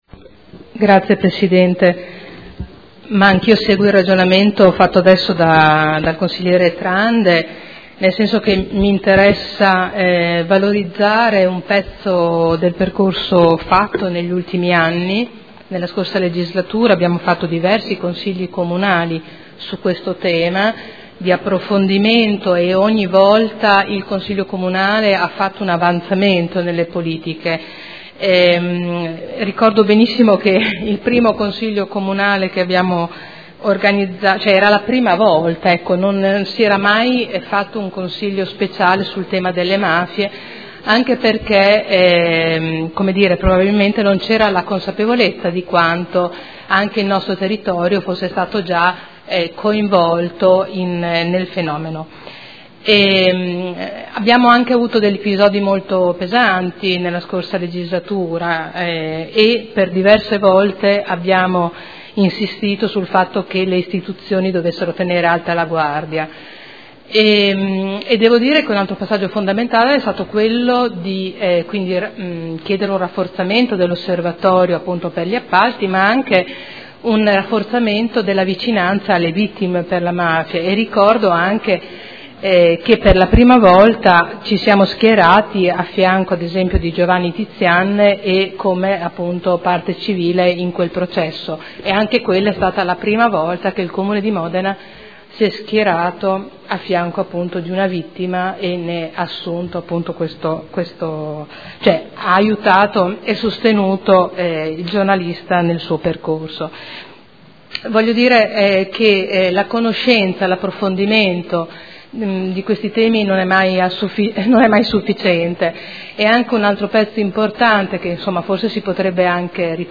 Seduta del 7/05/2015.
Audio Consiglio Comunale